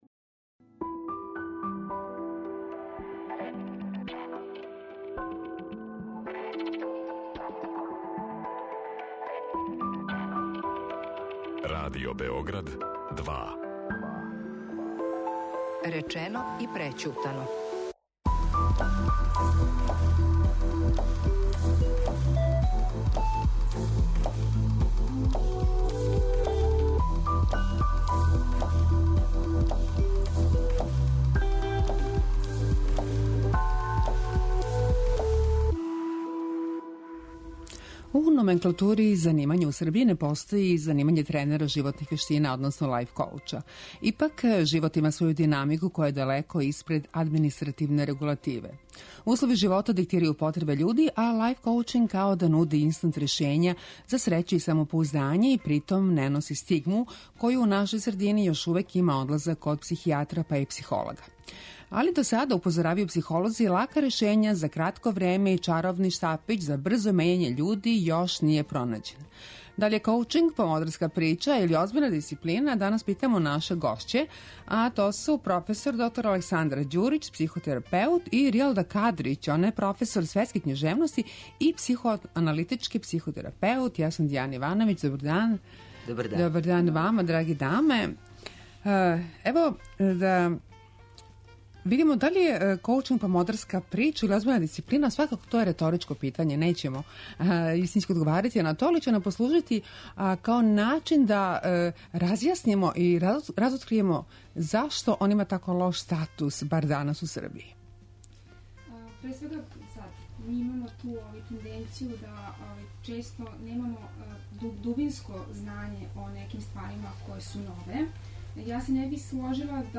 Гошће